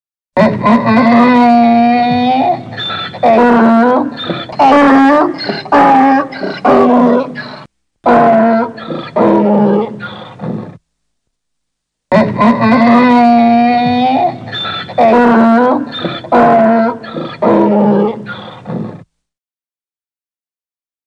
L’âne
il brait
Leur cri est le braiment qui est une sorte de « Hi-Han » assez strident et puissant absolument caractéristique.